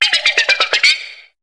Cri d'Écayon dans Pokémon HOME.